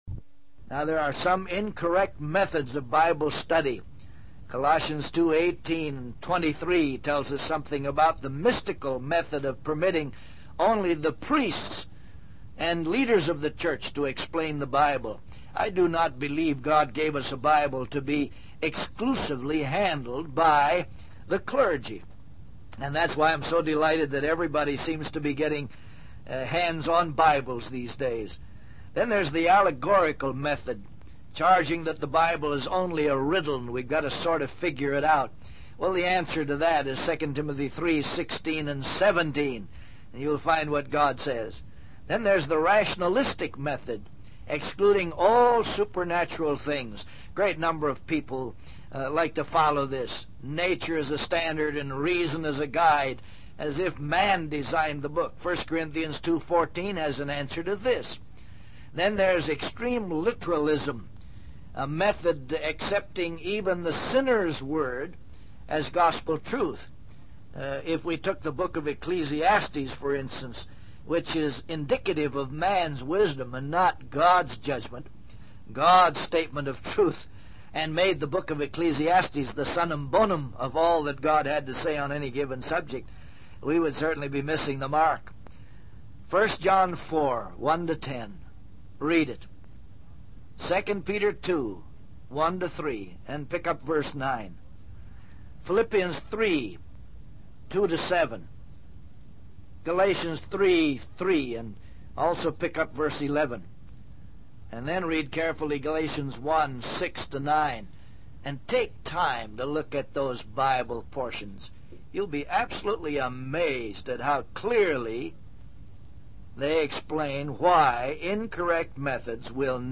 In this sermon, the speaker emphasizes the importance of winning others to Jesus Christ.